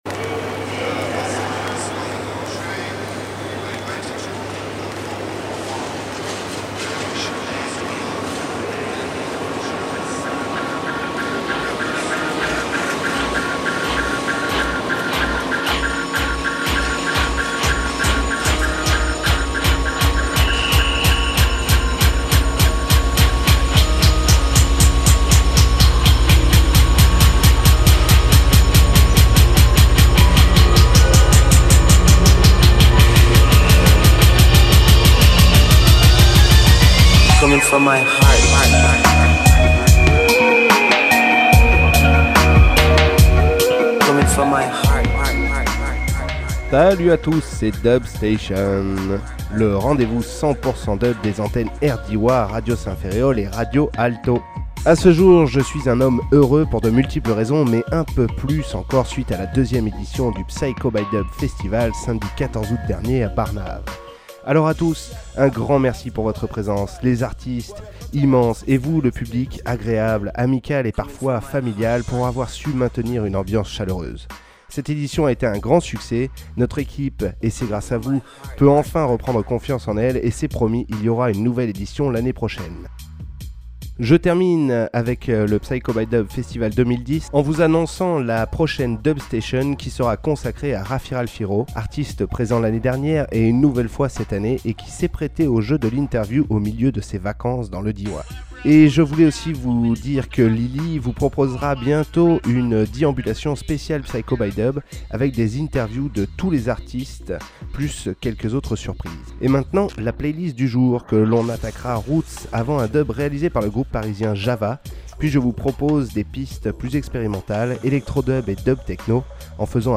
bass music , dub , musique , musique electronique , reggae